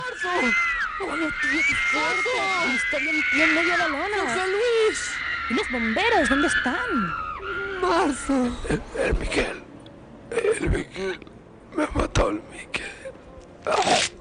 Ficció